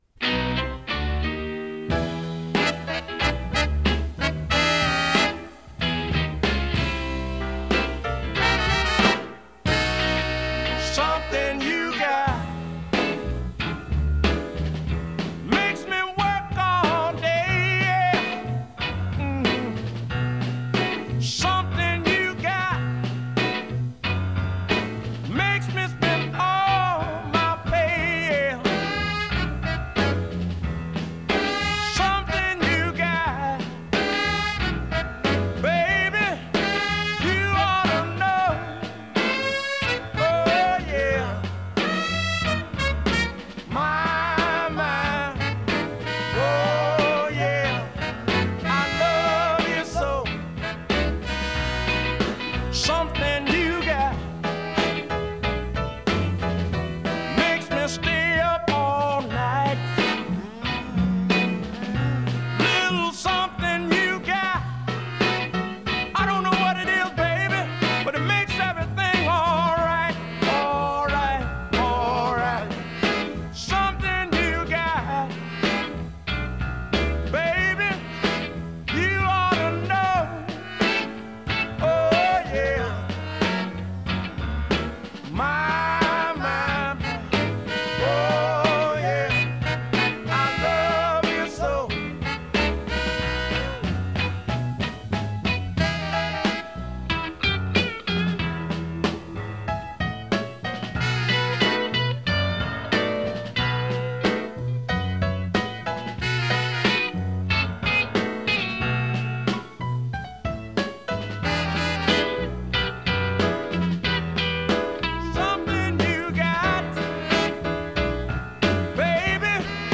southern soul